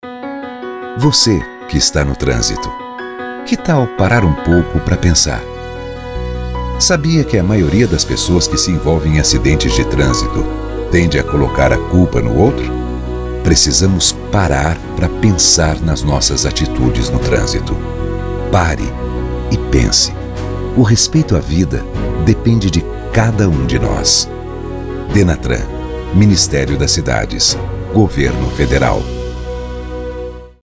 Spots de Rádio